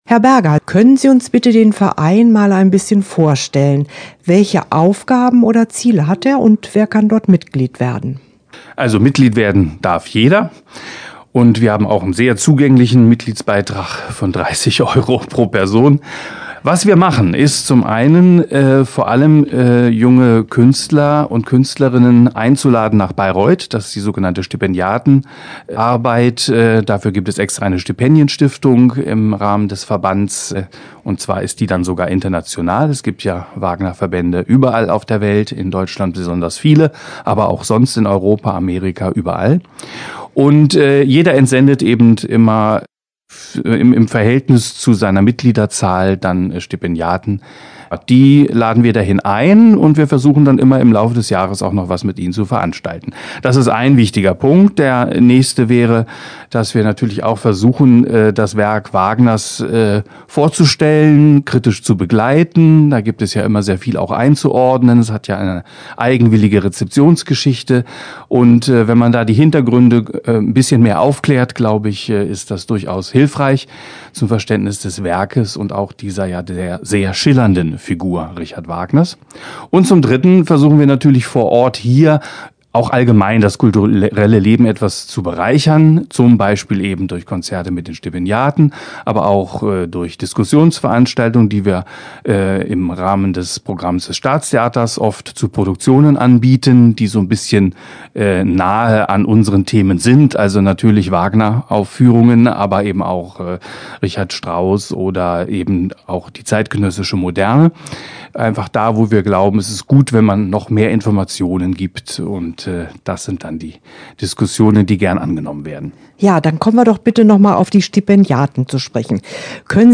Interview-Wagner-Verband.mp3